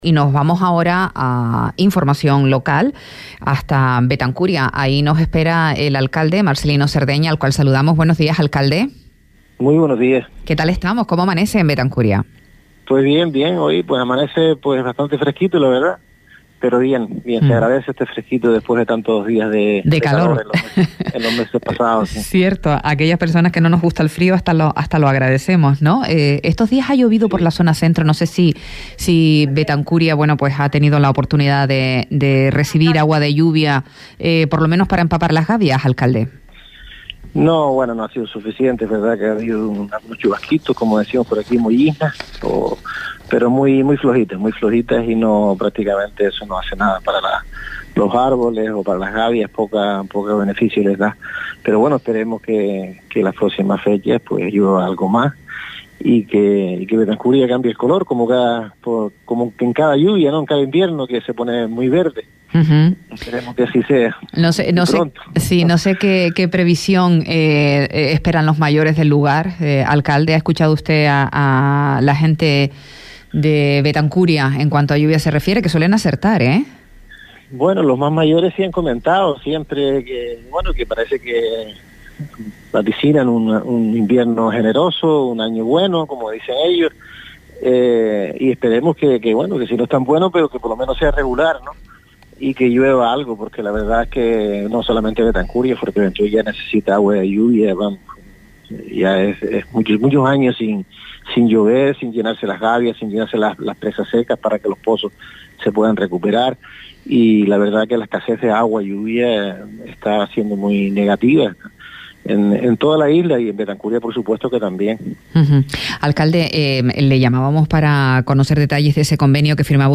A Primera Hora, entrevista a Marcelino Cerdeña, alcalde de Betancuria - 07.11.23 - Radio Sintonía
Entrevistas